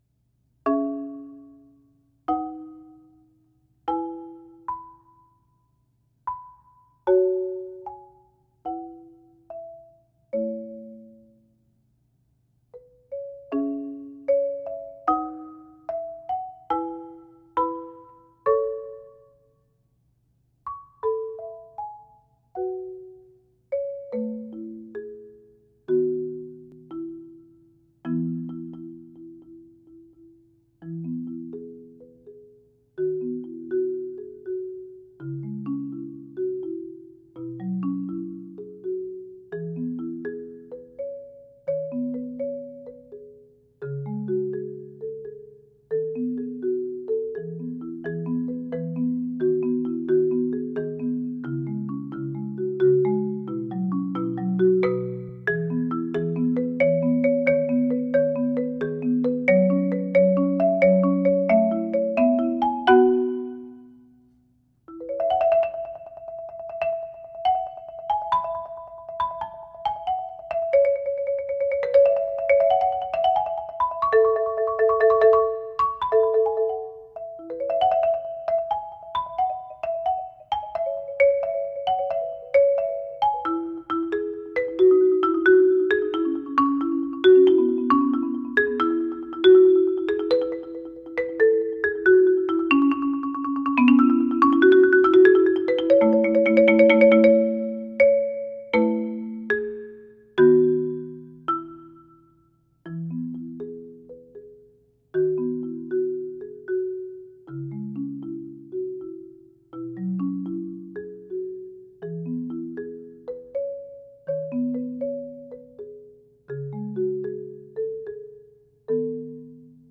Voicing: Marimba Solo